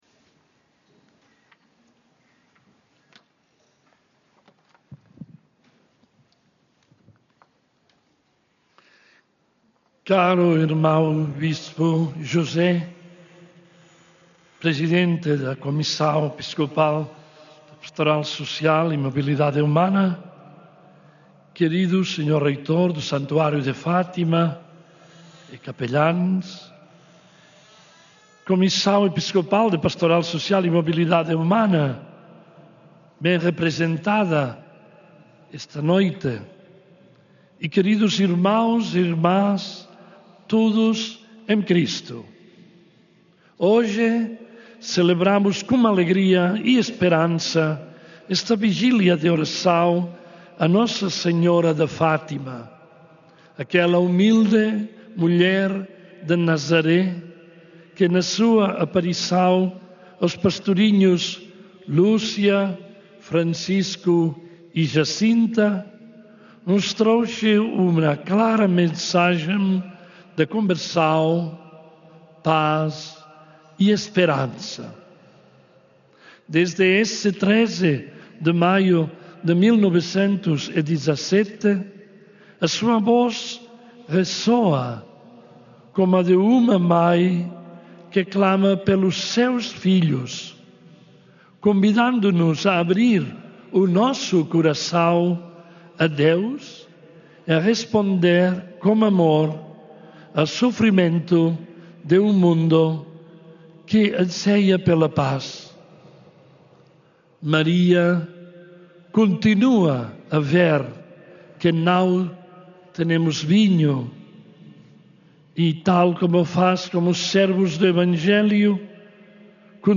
Na homilia que proferiu esta noite em Fátima, na celebração da Palavra da Peregrinação de 12 e 13 de agosto, D. Joan-Enric Vives apelou ao acolhimento, à proteção e integração dos migrantes, exortando os peregrinos reunidos no Recinto de Oração a assumir uma atitude fraternidade para com aqueles que vivem esta realidade, tal como o Evangelho exige.